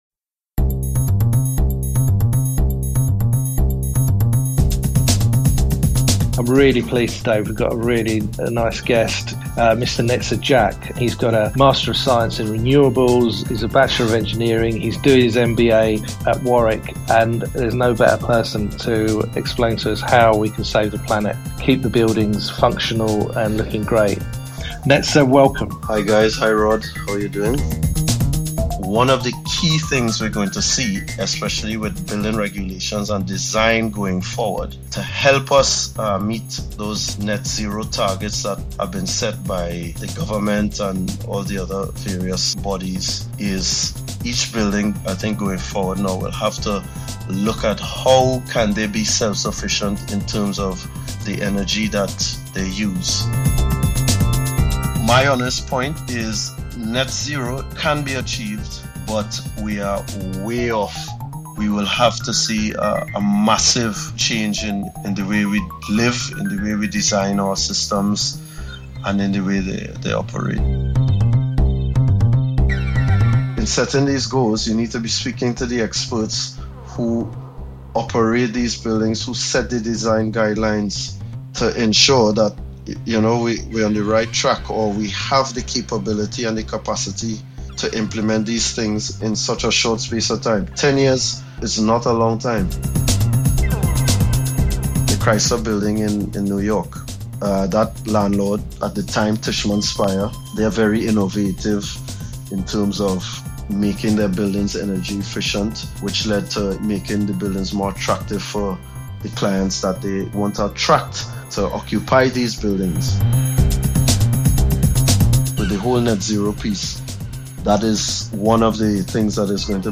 Preview of my interview